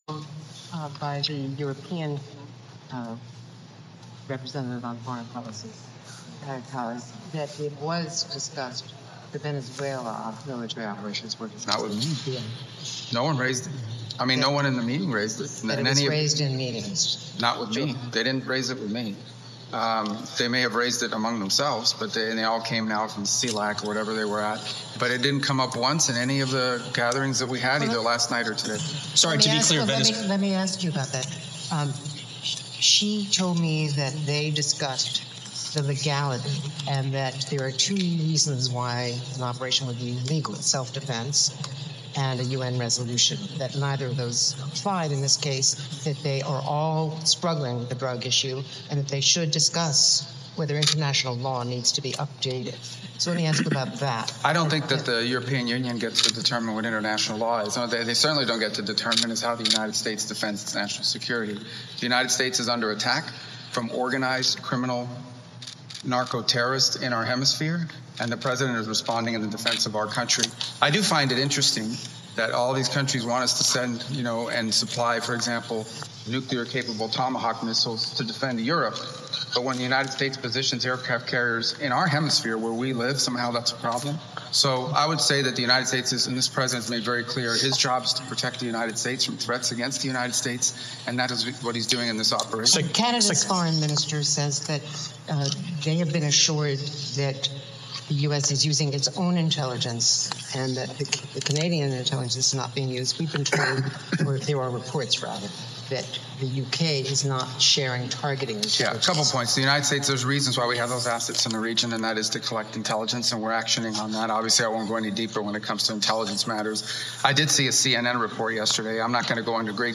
Marco Rubio: Pre G7 Press Conference at Hamilton Airport (transcript-audio-video)
[NOT AR AUTHENTICATED -- CHECK AGAINST DELIVERY. AUDIO BEGINS IN PROGRESS AT THE THIRD QUESTION]